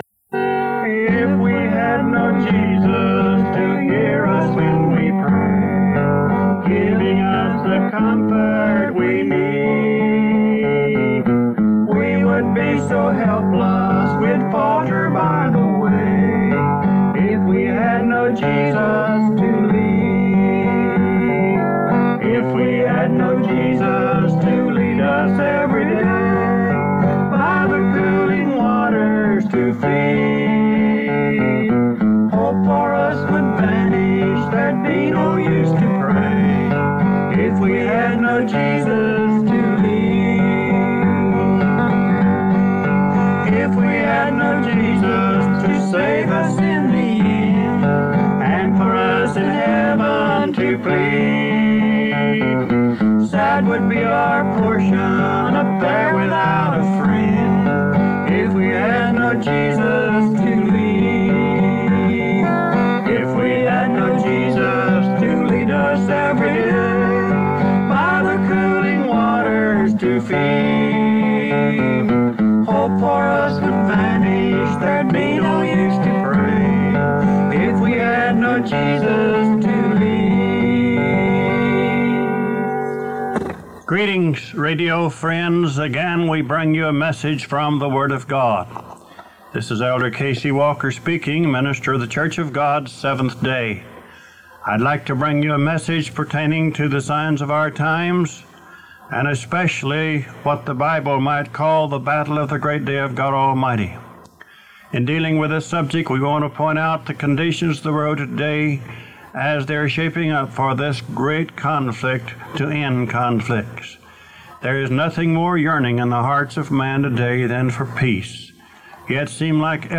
Radio broadcast from March 8